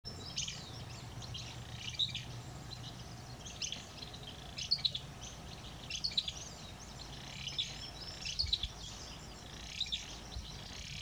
Fulvous-crowned Scrub Tyrant (Euscarthmus meloryphus)
Life Stage: Adult
Country: Argentina
Province / Department: Tucumán
Condition: Wild
Certainty: Photographed, Recorded vocal